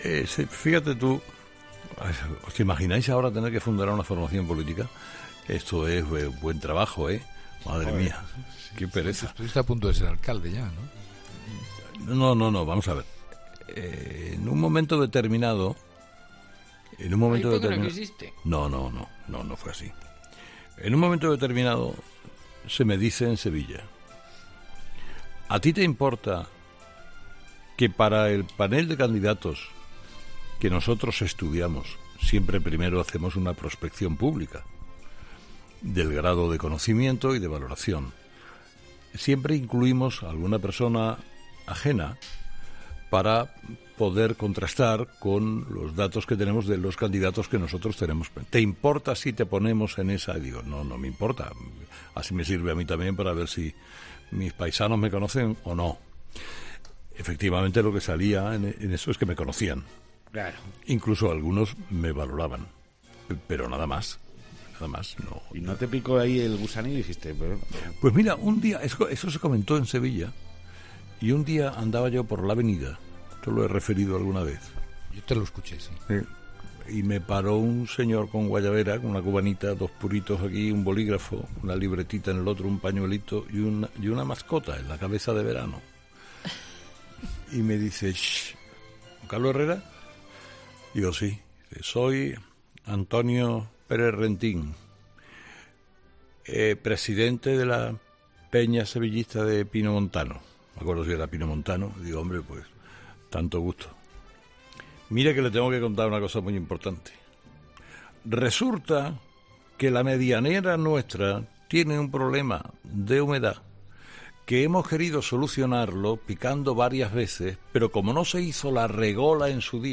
La pregunta se la planteaba al comunicador este miércoles uno de los colaboradores de 'Herrera en COPE'.